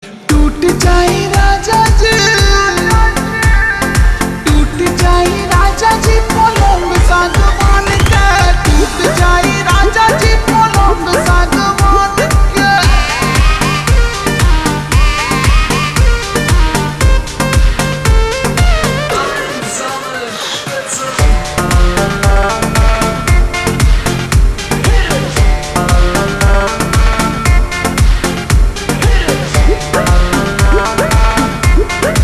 Bhojpuri Songs